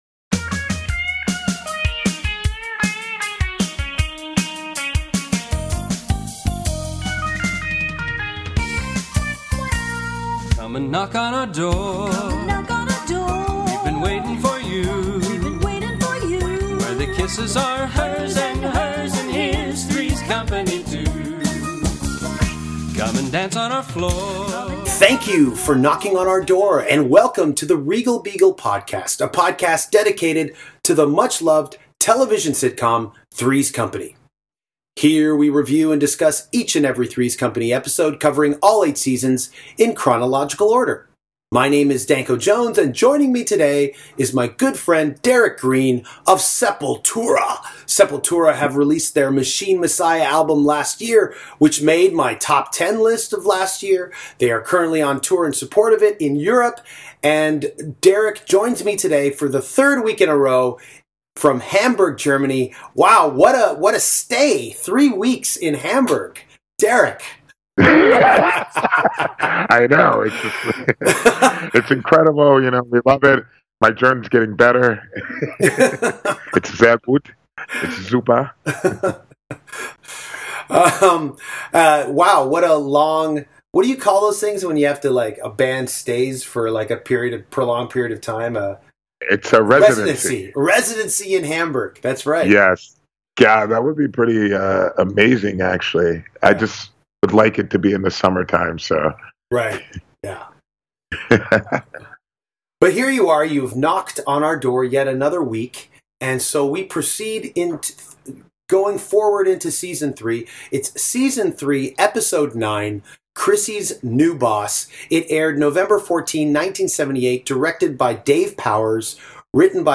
Danko’s co-host is Derrick Green (Sepultura)